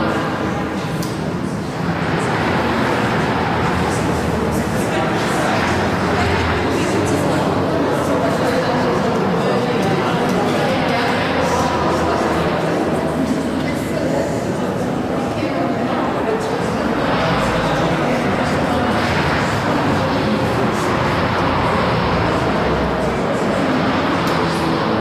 People2.ogg